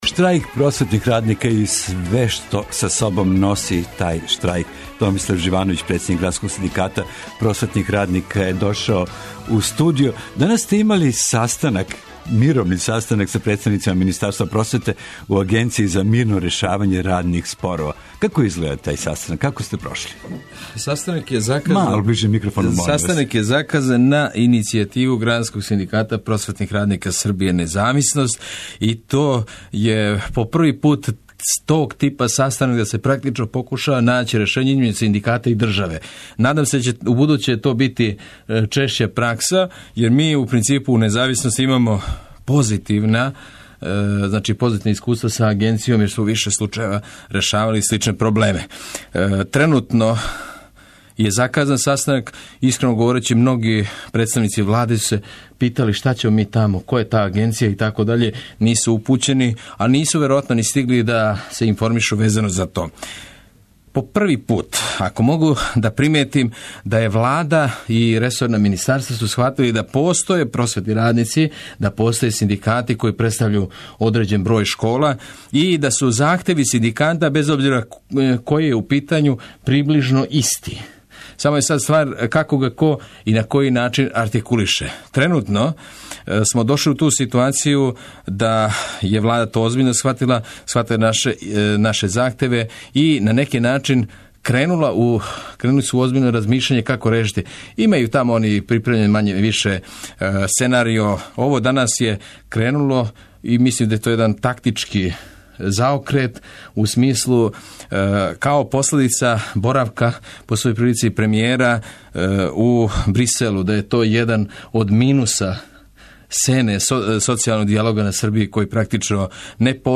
Данас у средишту пажње – штрајк просветних радника. Чућемо синдикалне поверенике из многих школа широм Србије.
Наши телефони отворени су за наставнике, професоре и родитеље.